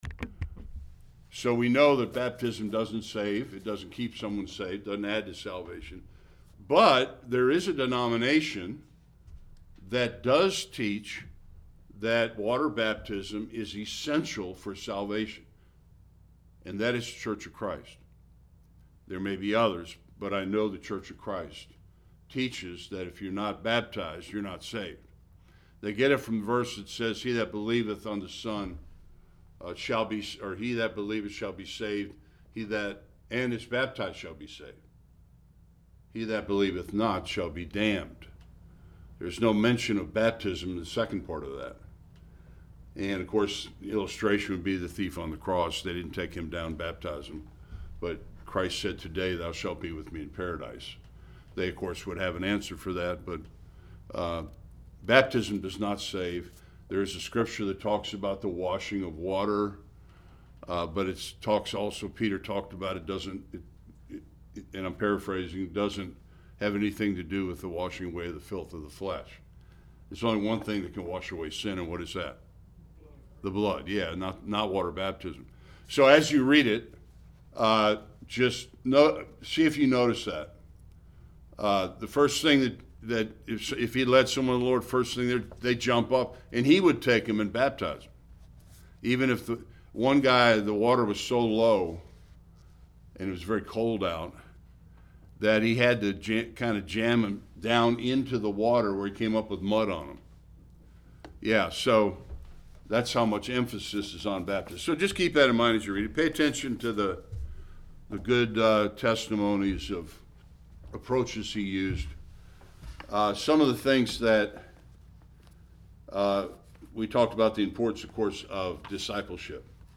Various Passages Service Type: Sunday School Some final suggestions for the soulwinner.